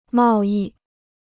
贸易 (mào yì): (commercial) trade.